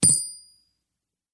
coin2.mp3